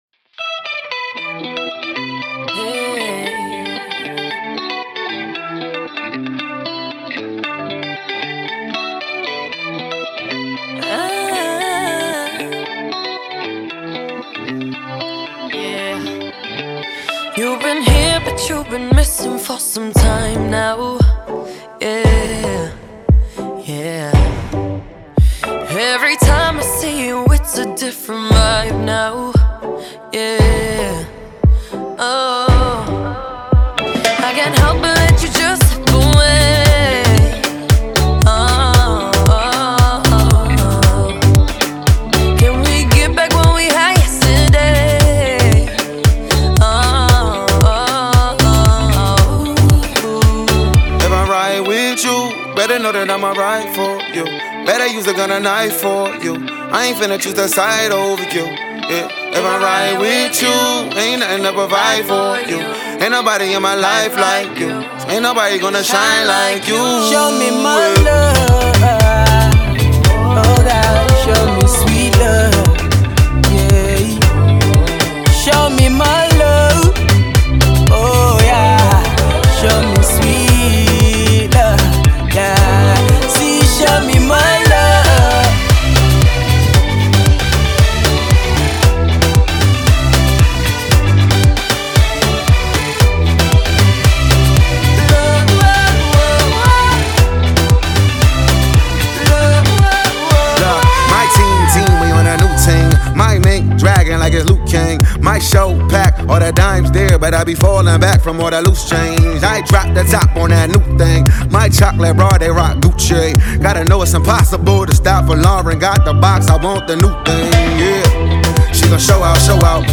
HipHop/Rnb
Hip-Hop
English female singer